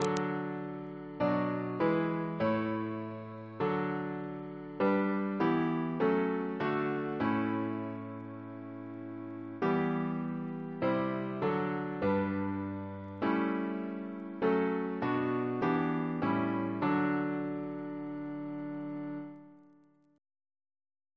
Double chant in D Composer: Edward John Hopkins (1818-1901), Organist of the Temple Church